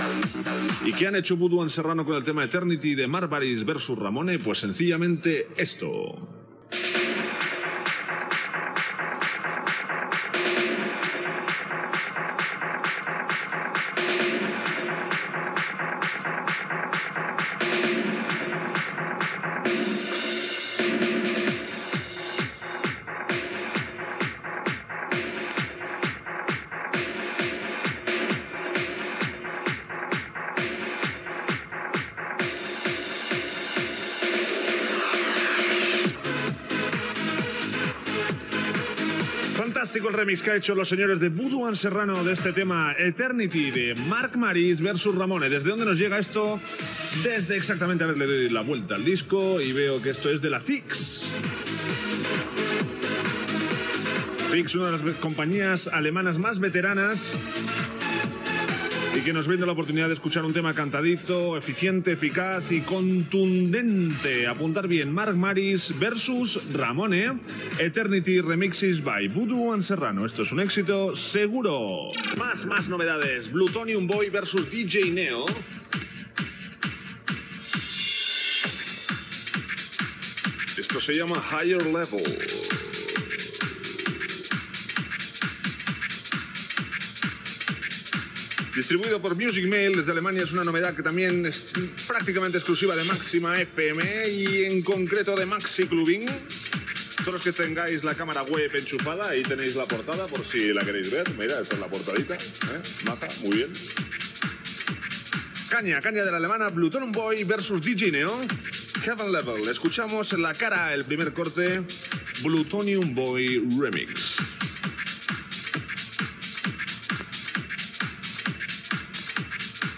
Temes musicals i presentacions
Musical